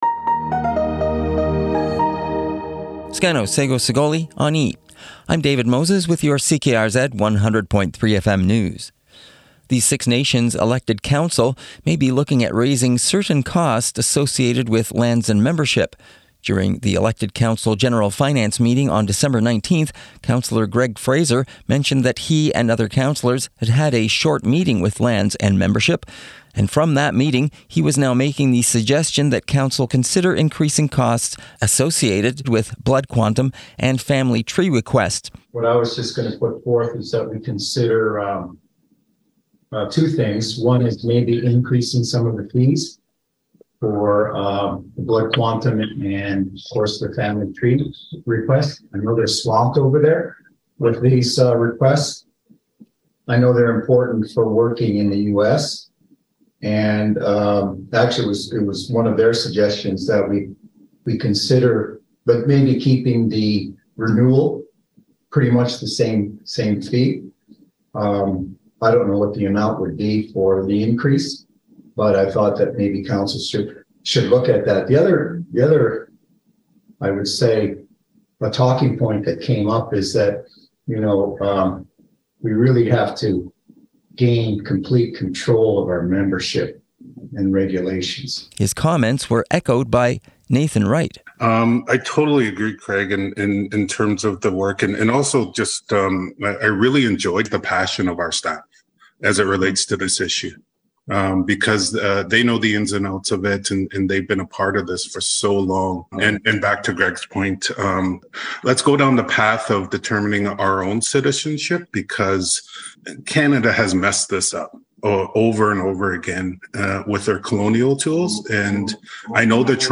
CKRZ